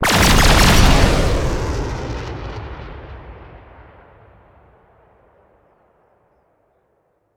ogg / ships / combat / weapons / salvotorpf.ogg